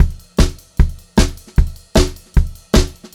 152SPCYMB1-R.wav